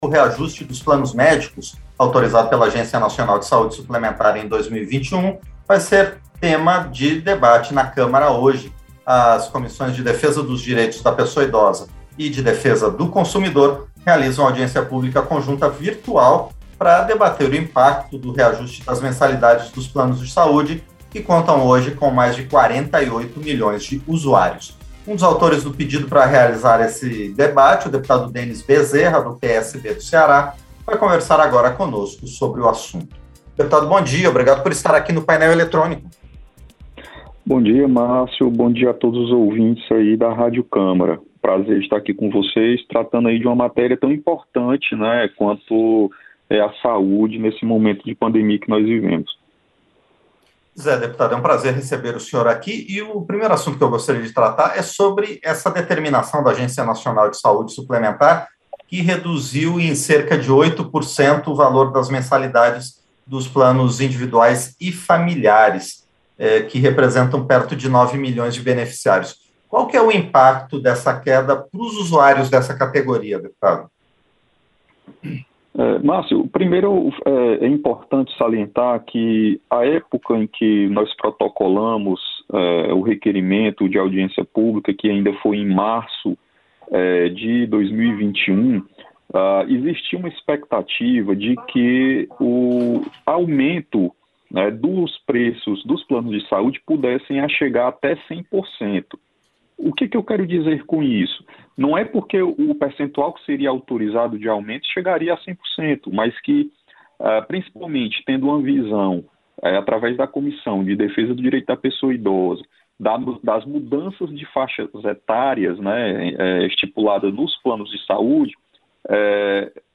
Entrevista - Dep. Denis Bezerra (PSB-CE)